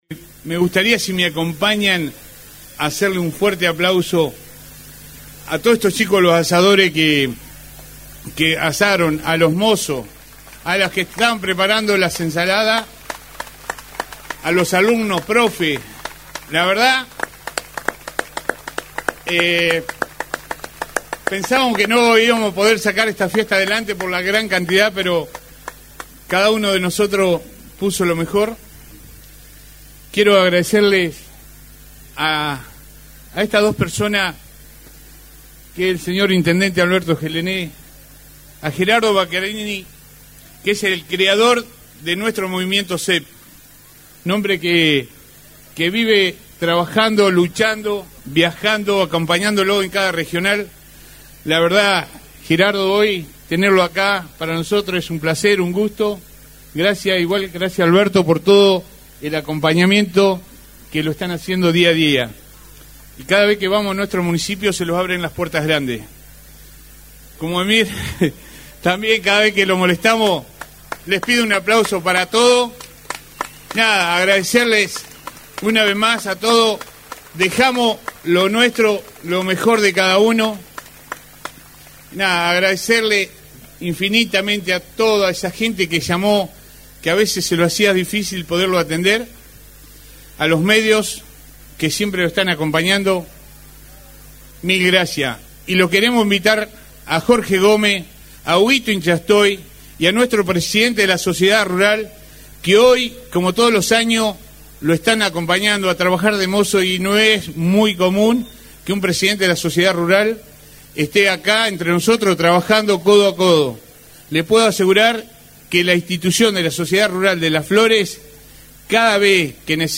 En lo protocolar, se escuchó la reflexión de: